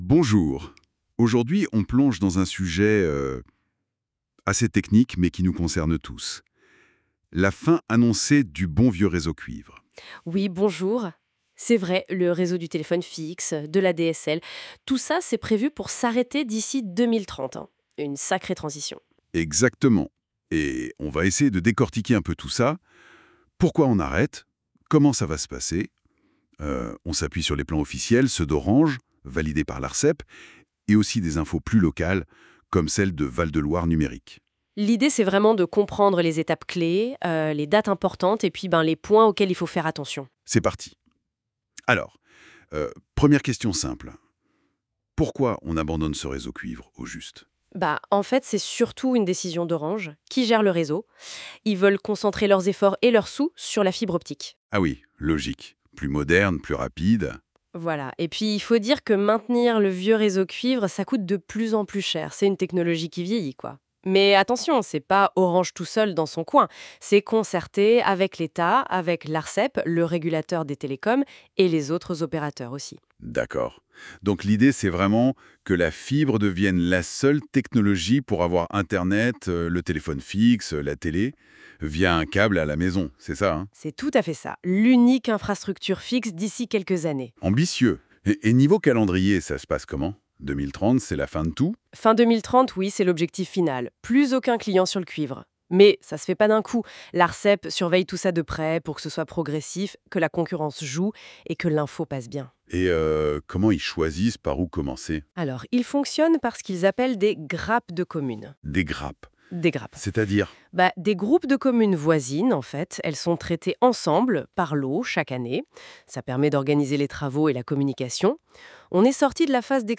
Contenu généré par IA sur la base des ressources du Syndicat.